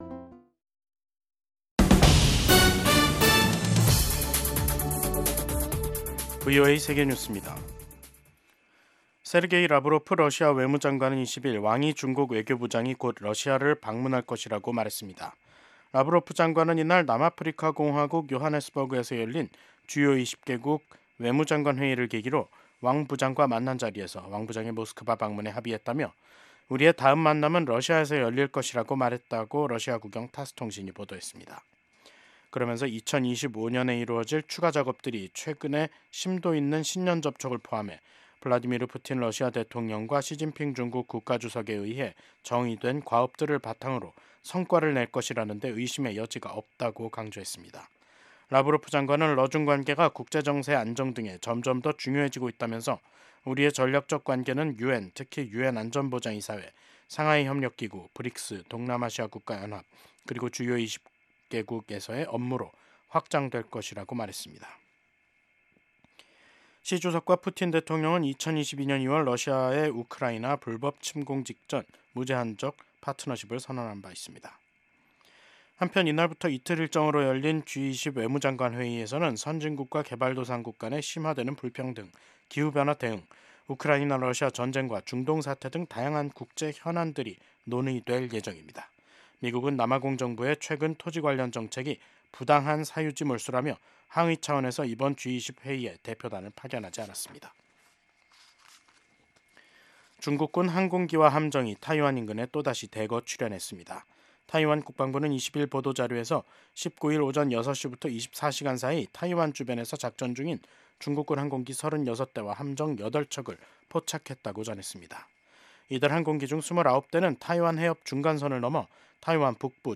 VOA 한국어 방송의 간판 뉴스 프로그램입니다. 한반도와 함께 미국을 비롯한 세계 곳곳의 소식을 빠르고 정확하게 전해드립니다. 다양한 인터뷰와 현지보도, 심층취재로 풍부한 정보를 담았습니다.